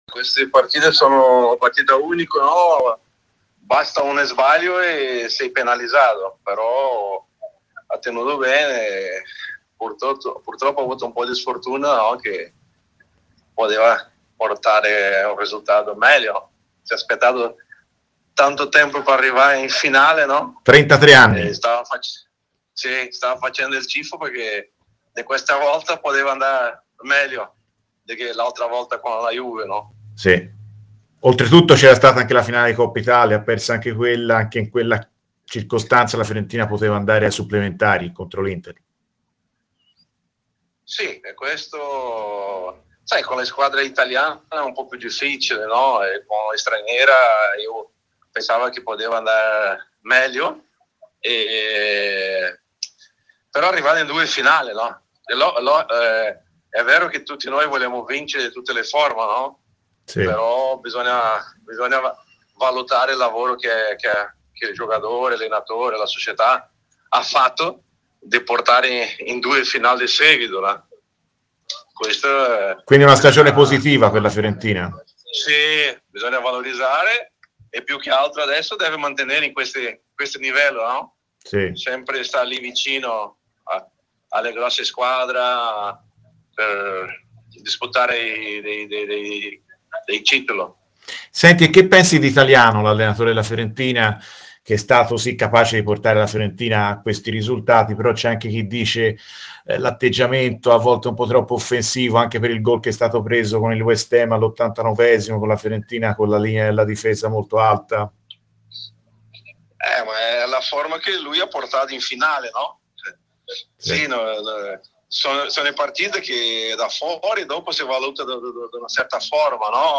Intervenuto a RadioFirenzeViola per commentare la stagione da poco conclusa della Fiorentina, l'ex centrocampista viola Carlos Dunga (con un passato anche da ct della Nazionale brasiliana), si è così espresso a proposito della squadra di Italiano nel corso della trasmissione "Viola amore mio", ripartendo dalla dolorosa sconfitta in finale di Conference League, a 33 anni dal ko in finale di Coppa Uefa contro la Juve giocata proprio dal "cucciolo": "Le finali secche le conosciamo tutti: basta uno sbaglio e sei penalizzato.